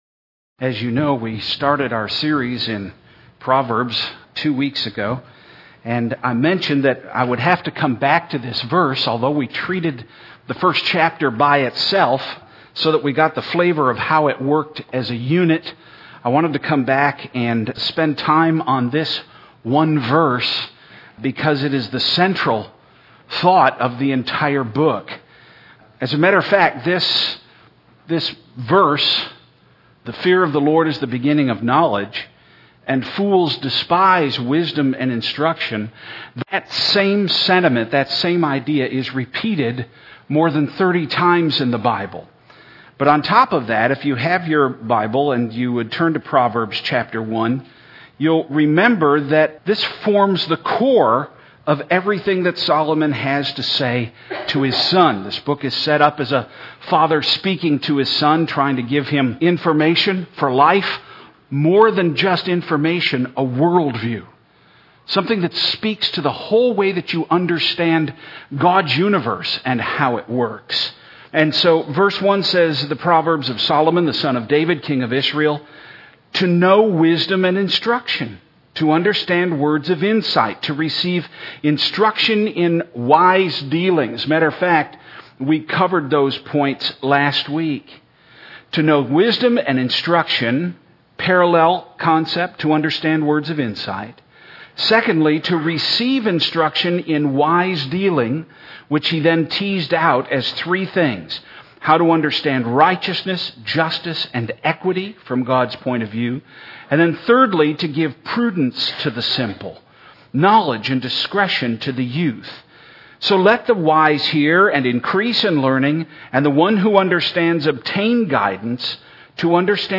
Sermons | Evangelical Church of Fairport